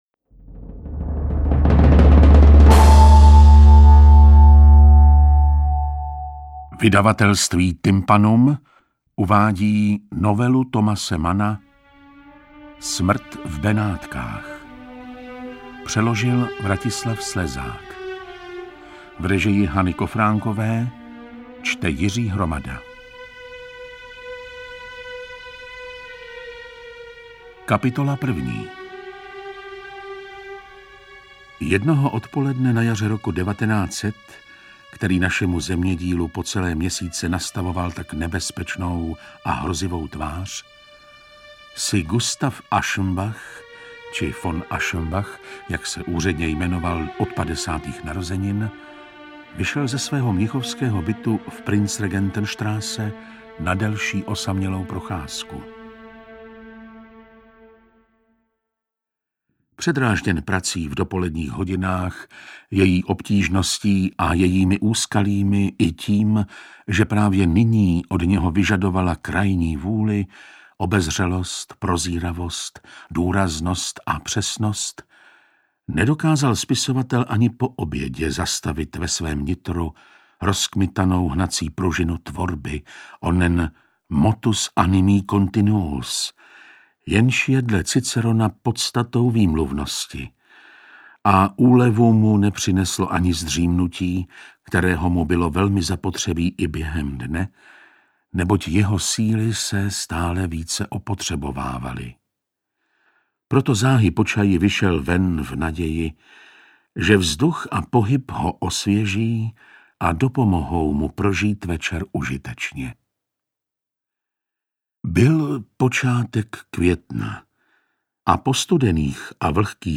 Interpret:  Jiří Hromada
AudioKniha ke stažení, 13 x mp3, délka 3 hod. 55 min., velikost 322,2 MB, česky